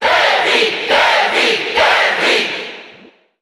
Category: Crowd cheers (SSBU) You cannot overwrite this file.
Terry_Cheer_Italian_SSBU.ogg.mp3